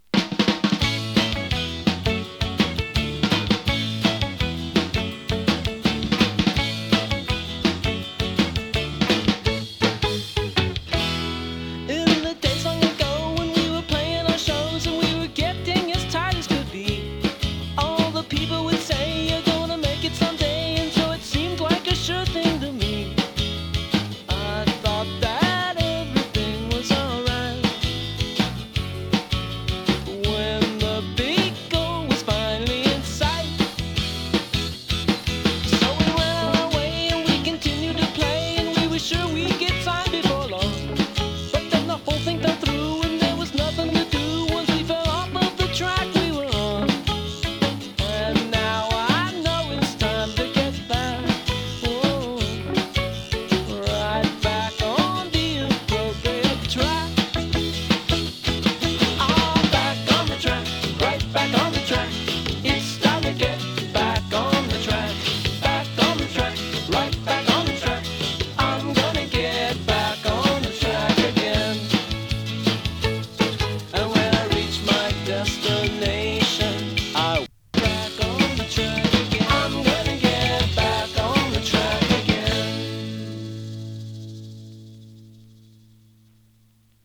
ラヴリーパワーポップ
インディーポップ